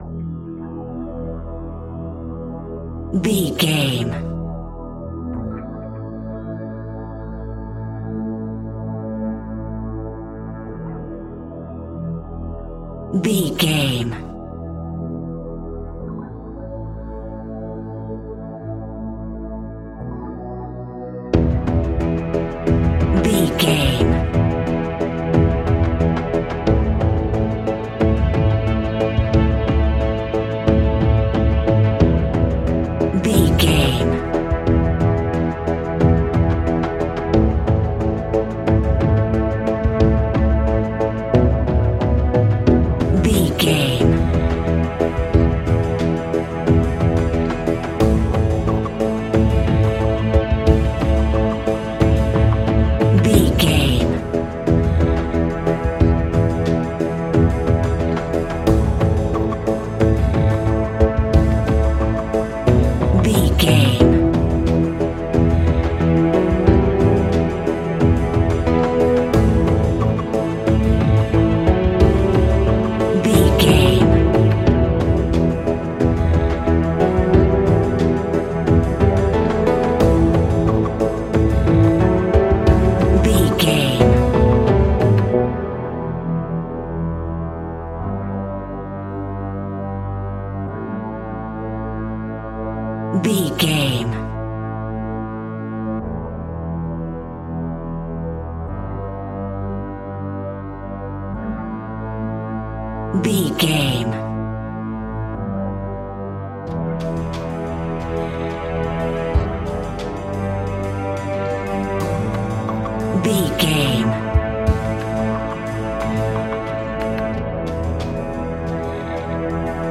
Aeolian/Minor
ominous
dark
haunting
eerie
percussion
synthesizer
horror music
horror piano